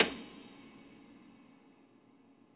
53a-pno27-D6.aif